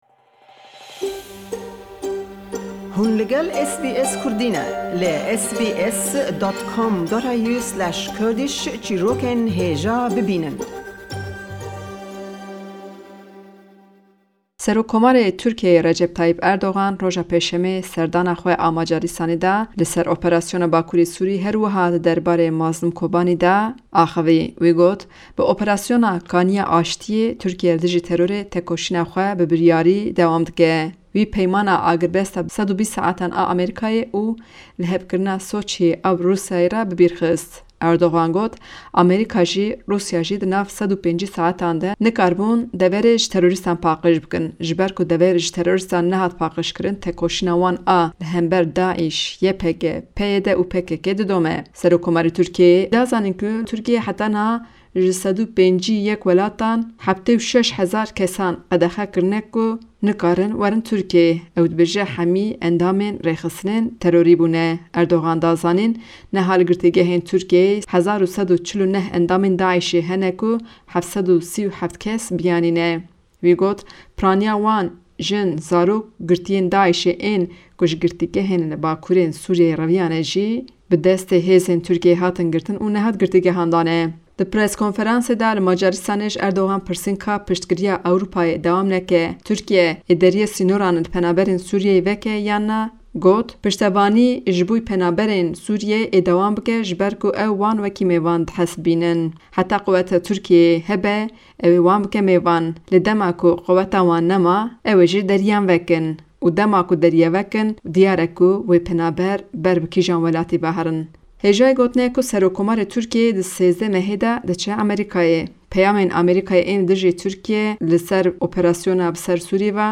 Rapoerta